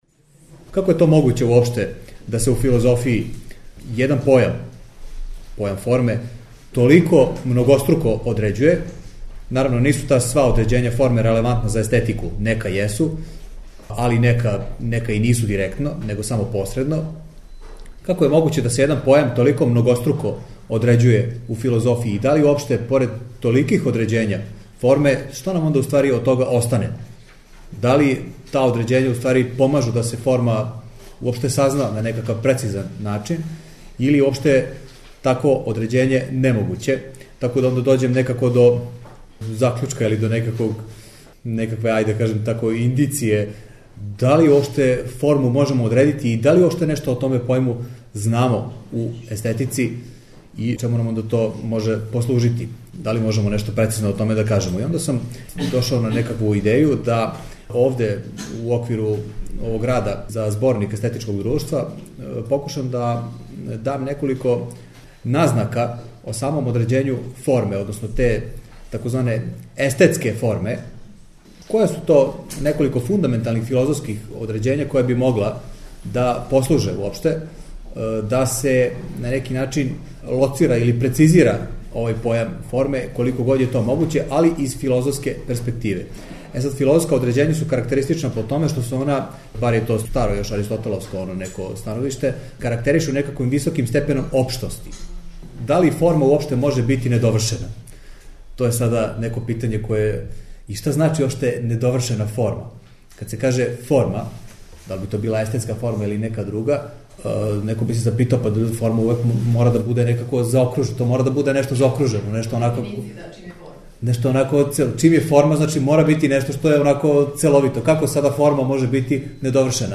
Научни скупови